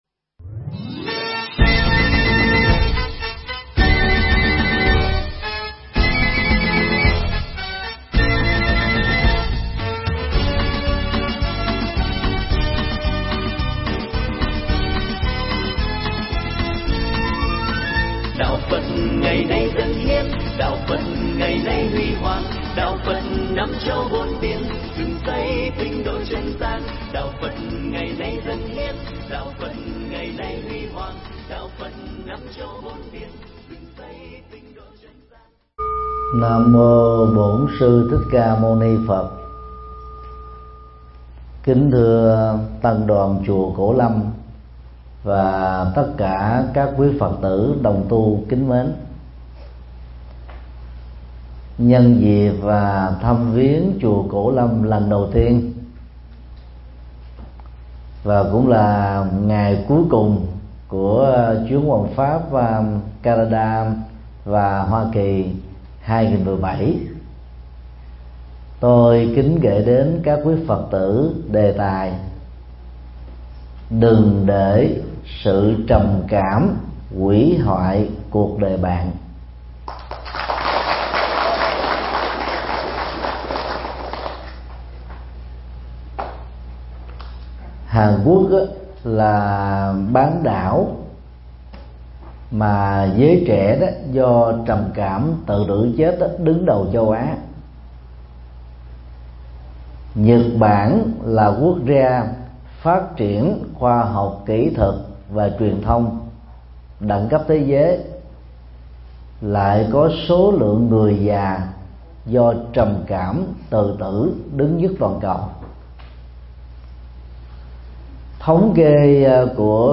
Mp3 Thuyết Giảng Đừng Để Trầm Cảm Hủy Hoại Cuộc Đời Bạn
giảng tại chùa Cổ Lâm (Hoa Kỳ)